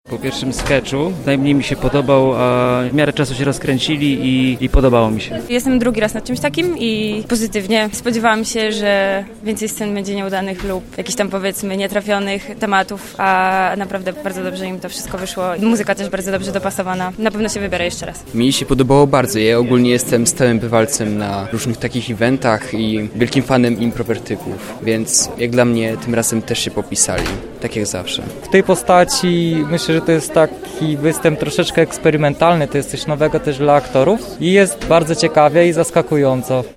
Nasza reporterka zapytała widzów o ich wrażenia po występie: